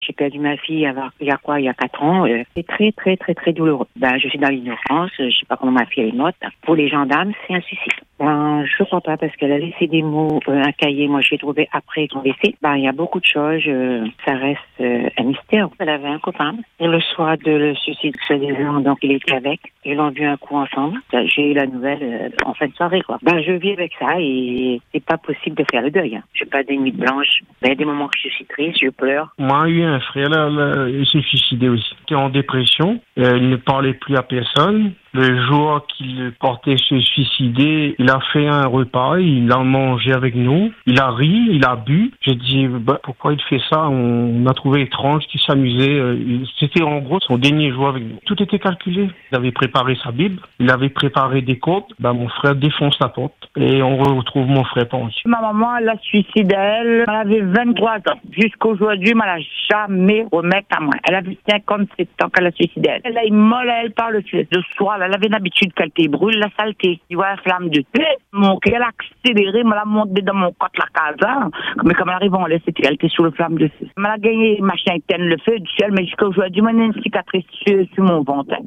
Sur l’antenne, ces récits ont été diffusés avec retenue, dans un esprit de partage et de prévention, afin de rappeler que derrière chaque drame, il y a des familles, des proches, et des vies durablement bouleversées.